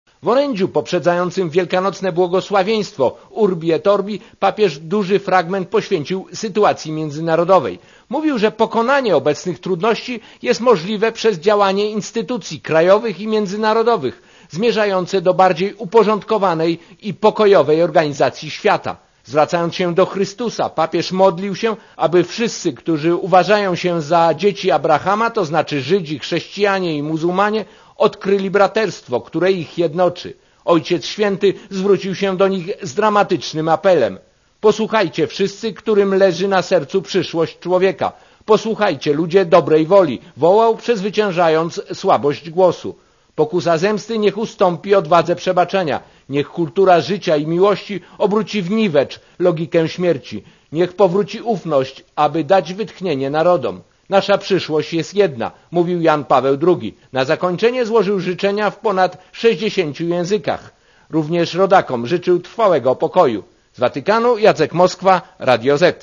Korespondancja z Watykanu